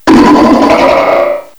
cry_not_mega_medicham.aif